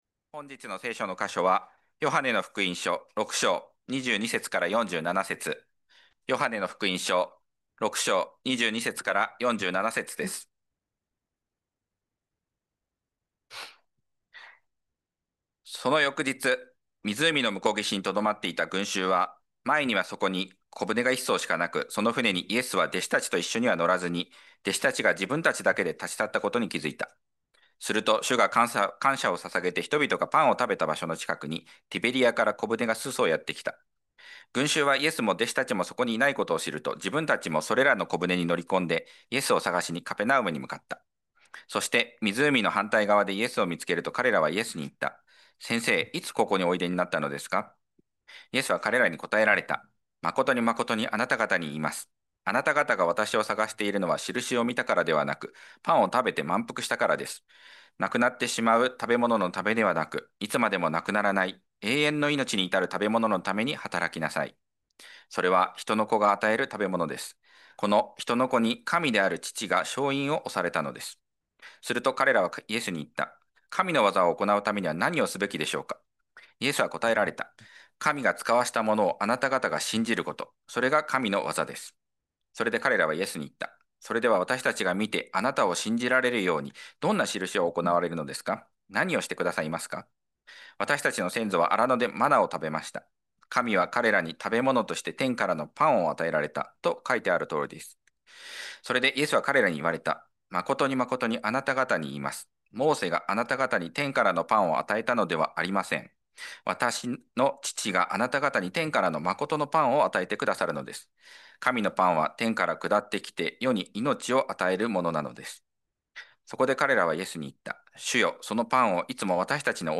2025年9月21日礼拝 説教 「説教者であるイエス様」 – 海浜幕張めぐみ教会 – Kaihin Makuhari Grace Church